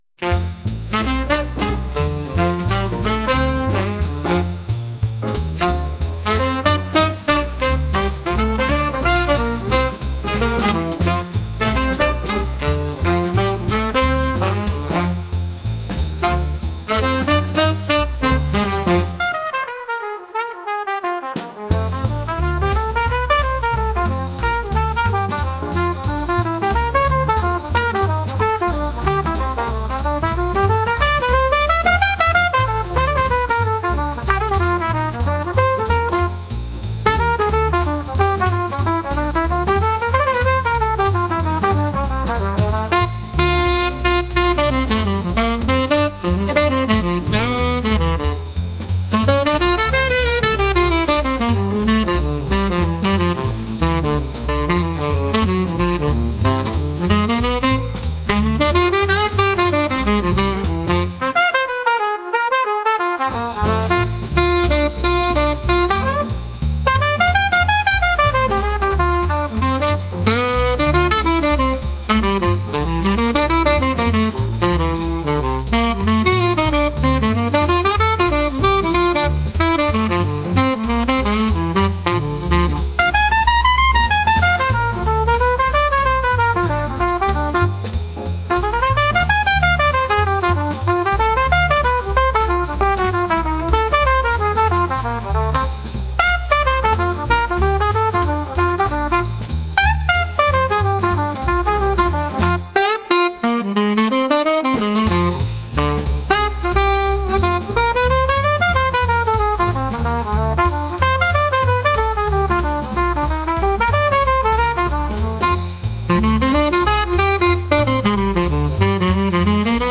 トランペット多めのクラシックジャズ曲です。